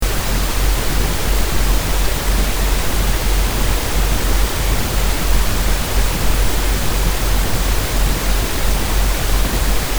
sound file, which I looped while I slept - it contains ocean wave-like noise with phase modulation of about 5 HZ [right on the border between the delta and theta range]. I also found this to put me into a very restful sleep, with plenty of dreams.